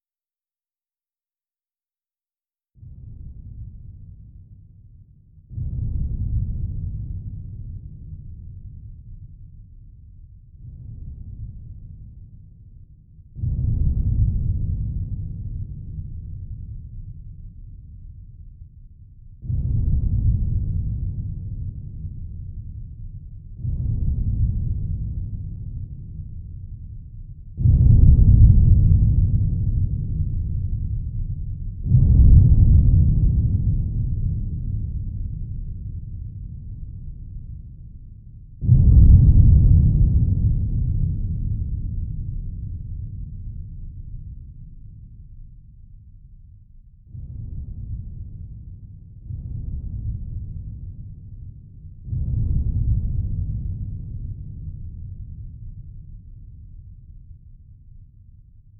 0335ec69c6 Divergent / mods / Soundscape Overhaul / gamedata / sounds / ambient / soundscape / underground / under_27.ogg 978 KiB (Stored with Git LFS) Raw History